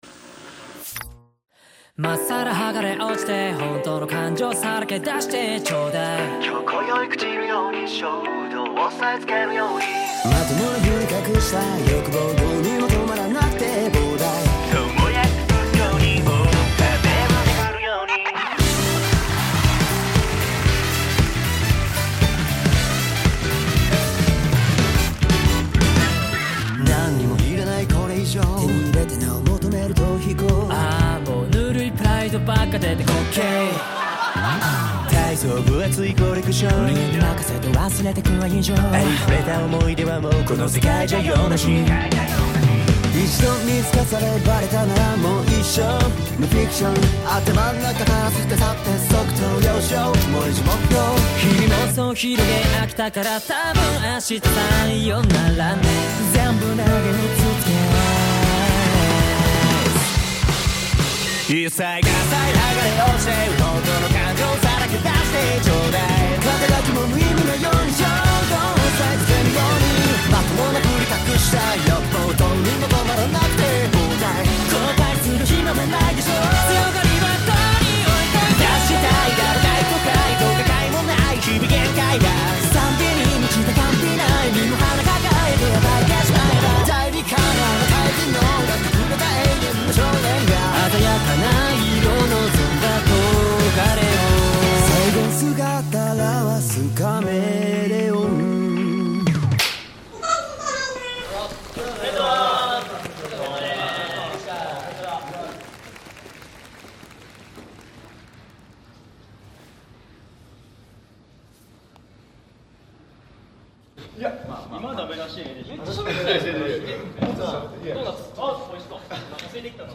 Label JPop